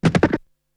Beatbox 7.wav